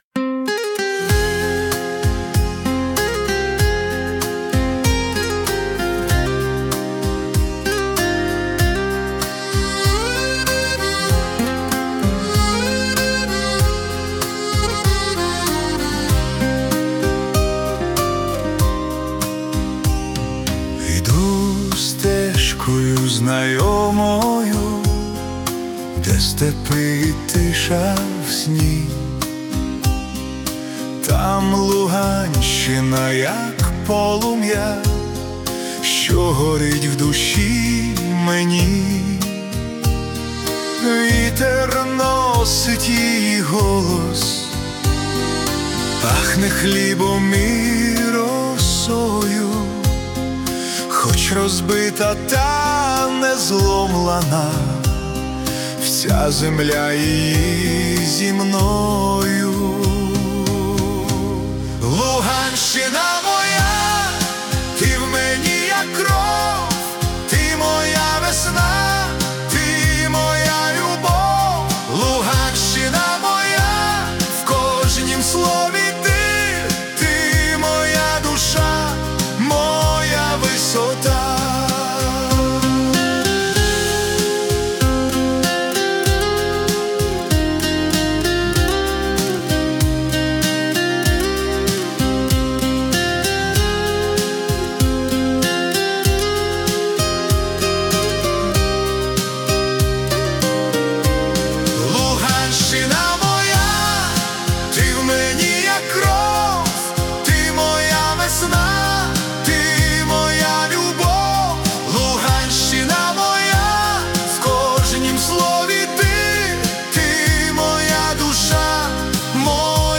🎵 Жанр: Ukrainian Chanson
прониклива шансон-балада